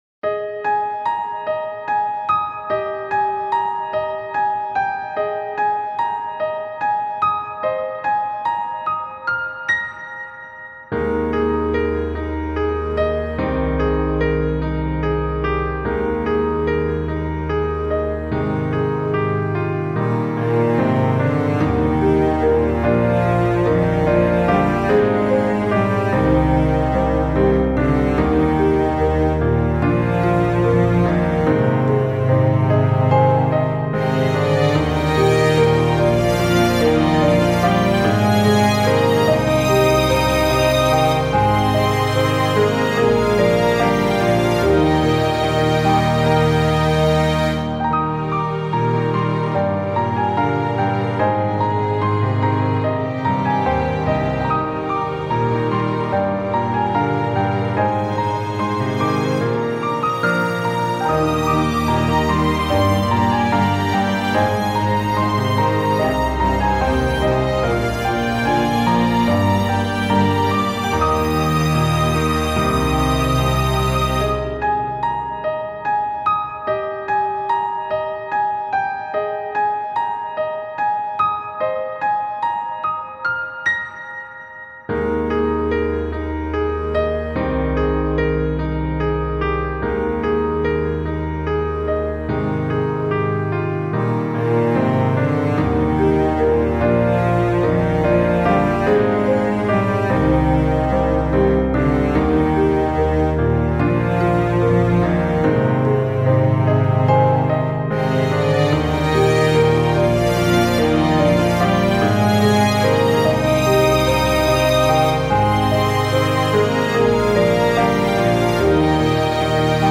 綺麗な星空の下で2人肩を寄せ合うような、優しい雰囲気のBGMです。
ピアノ ストリングス バラード ヒーリング
優雅 別れ 切ない 愛 星 癒し 感動 爽やか 穏やか